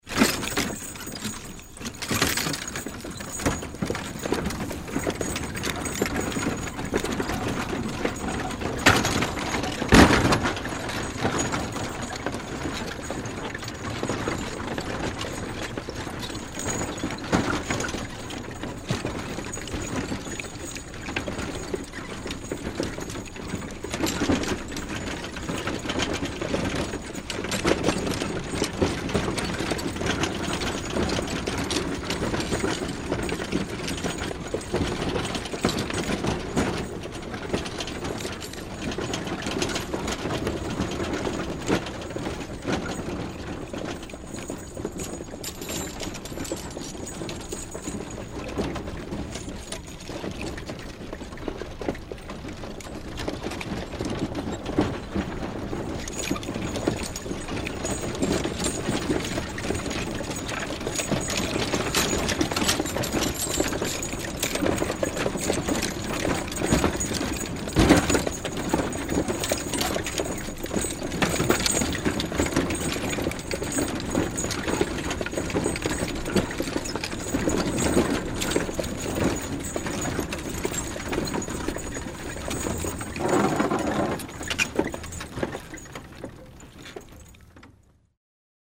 Здесь собраны натуральные записи: от мерного цокота копыт до характерного скрипа деревянных осей.
Звук: лошадь везет телегу